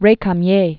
(rākəm-yā, rā-käm-)